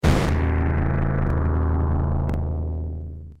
gameover_death2.wav